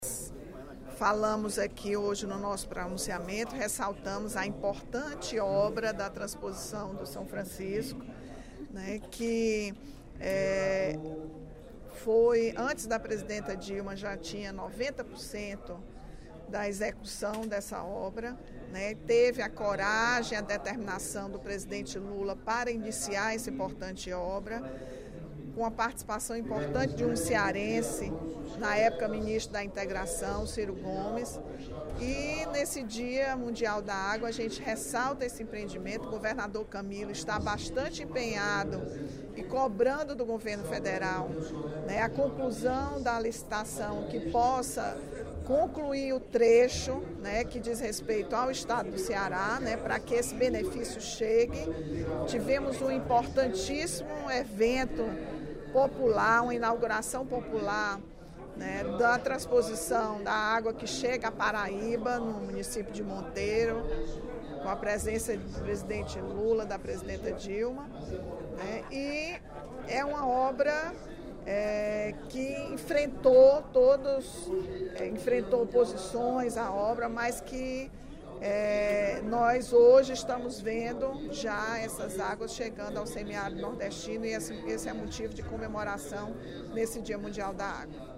O deputado Capitão Wagner (PR) apontou supostas irregularidades, durante o primeiro expediente da sessão plenária desta quarta-feira (22/03), no processo judicial que investiga a participação de policiais militares na chacina da Grande Messejana, que deixou 11 mortos em novembro de 2015.